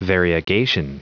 Prononciation du mot variegation en anglais (fichier audio)